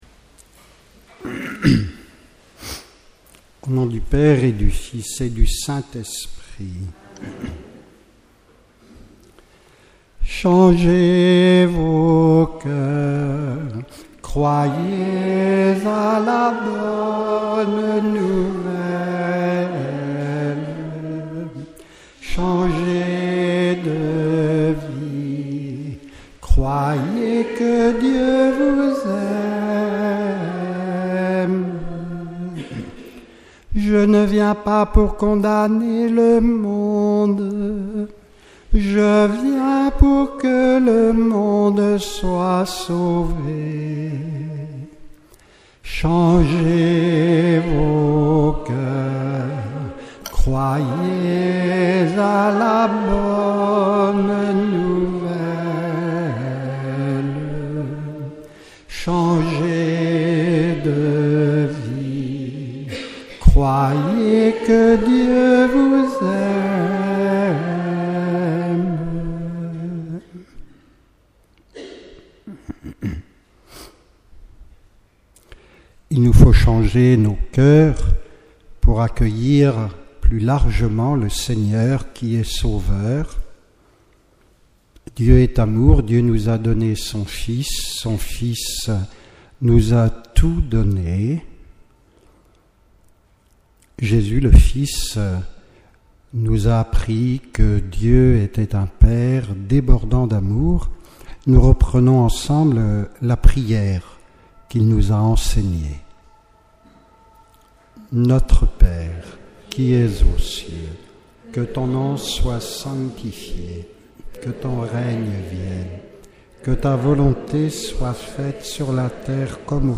2e conférence de carême 2018